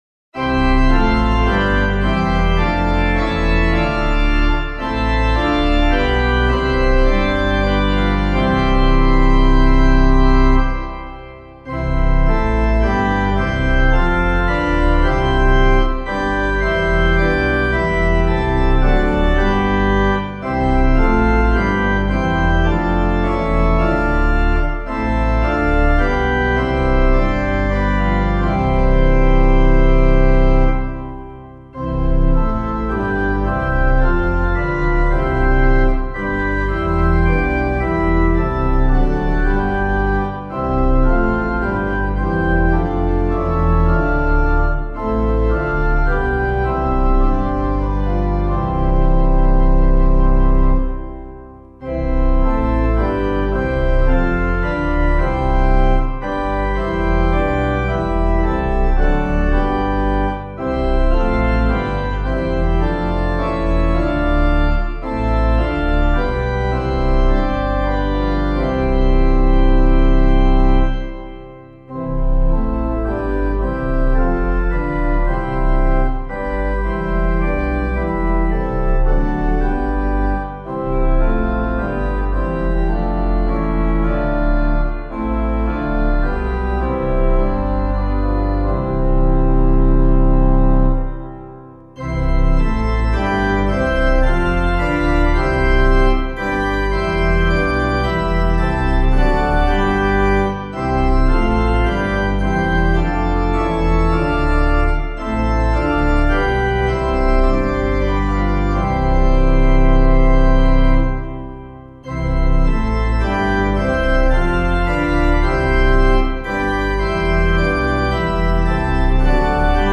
Meter:    77.77